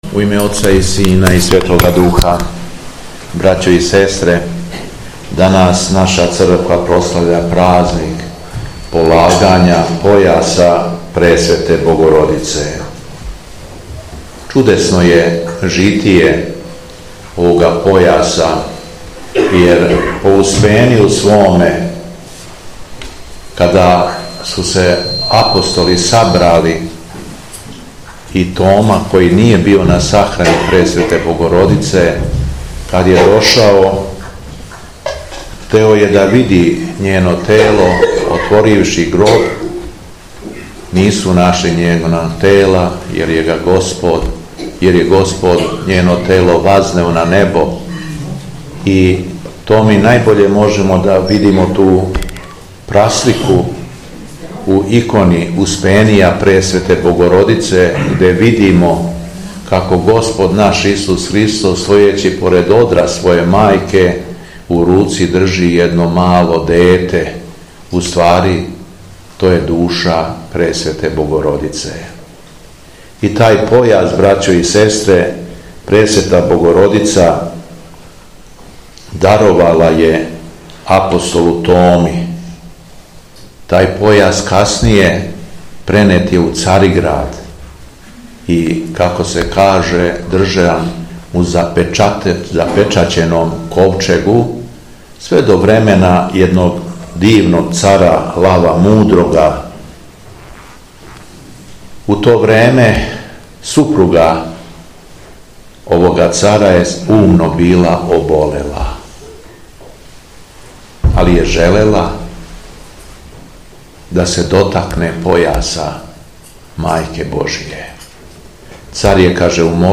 Беседа Његовог Високопреосвештенства Митрополита шумадијског г. Јована
Митрополит Јован је након читања Светога Јеванђеља вернимачеститао славу храма и произнео беседу о Пресветој Богородици: